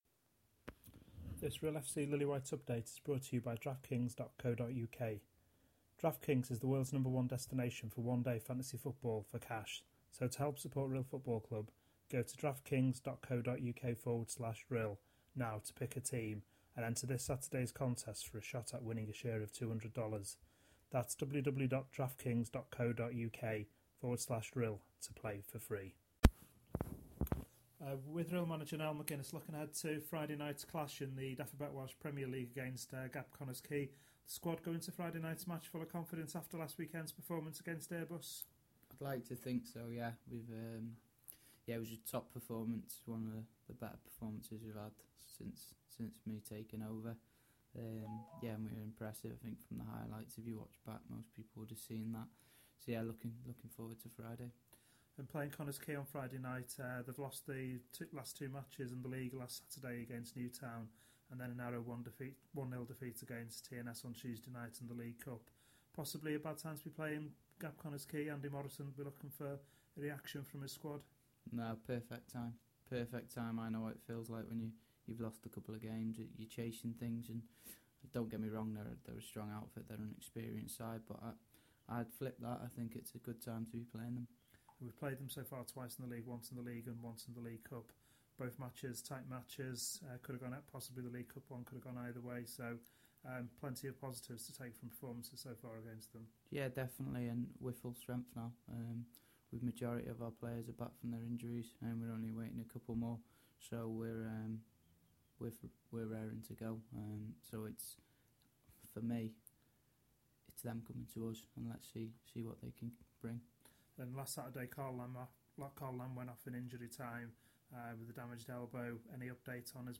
Pre - match interview - Gap Connah's Quay